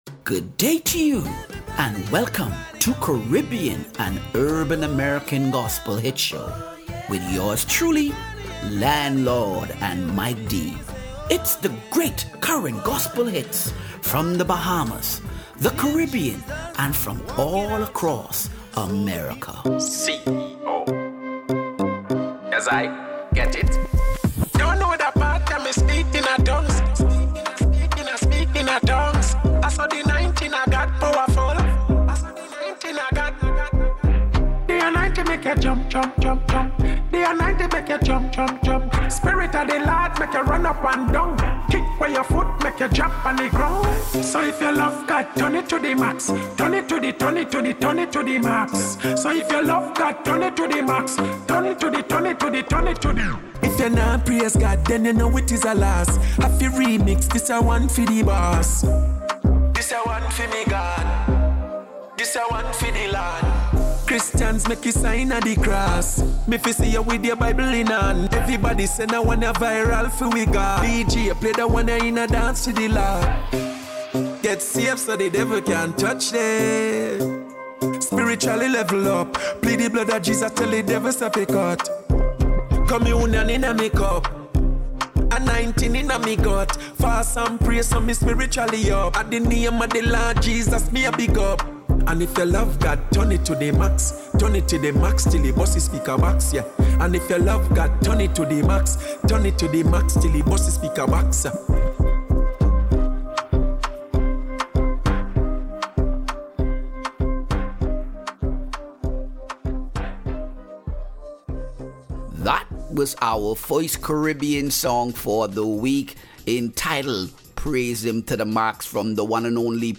Caribbean and Urban American Gospel Hits - April 6 2025